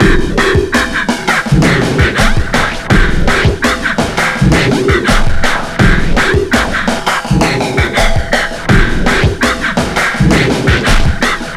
Index of /90_sSampleCDs/Best Service ProSamples vol.40 - Breakbeat 2 [AKAI] 1CD/Partition A/WEIRDBEAT083